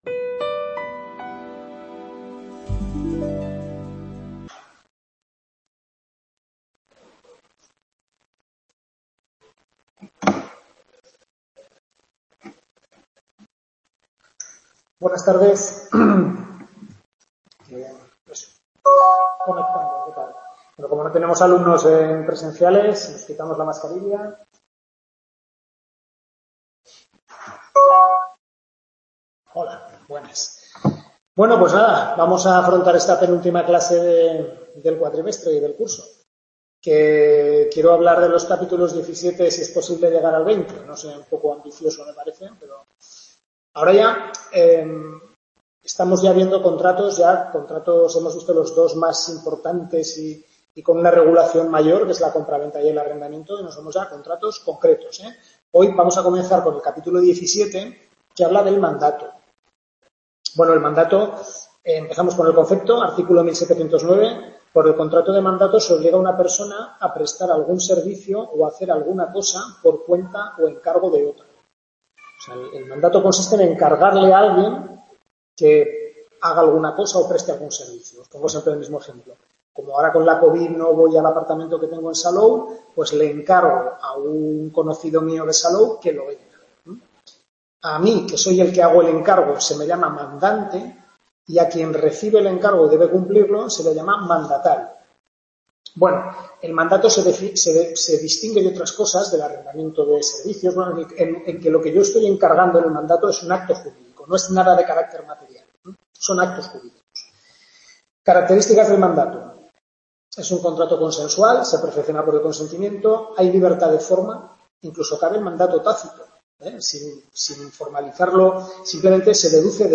Tutoría de Civil II (Contratos)